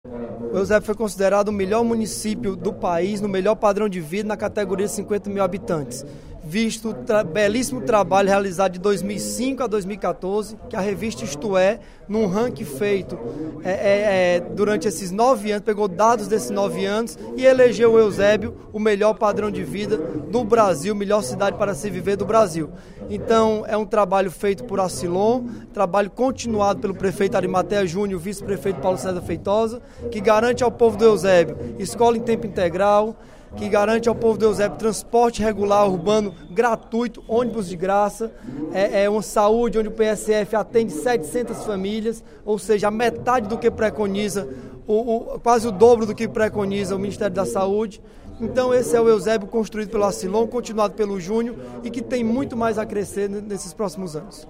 O deputado Bruno Gonçalves (PEN), em pronunciamento nesta terça-feira (22/09), durante o primeiro expediente da sessão plenária da Assembleia Legislativa, anunciou que o município de Eusébio foi apontado pela revista paulista “Isto É” como o melhor padrão de vida do País em cidades com até 50 mil habitantes.